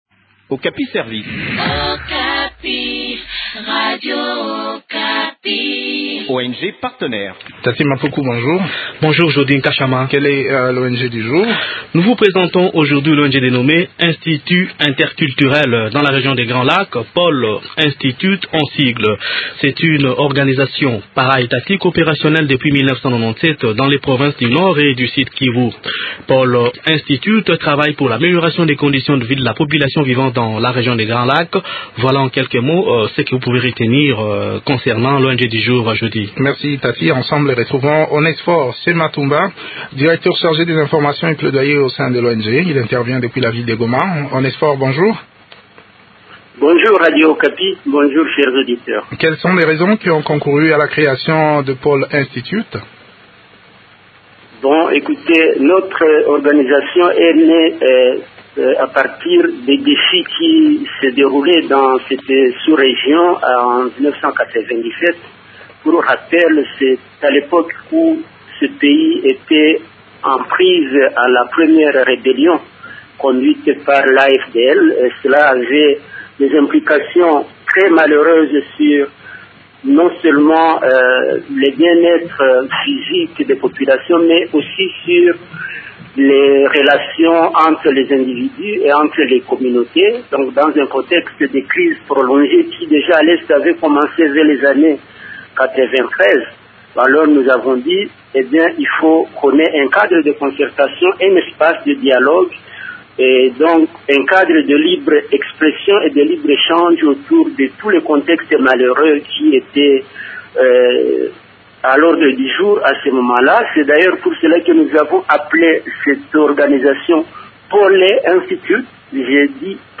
Découvrons les activités de cette structure dans cet entretien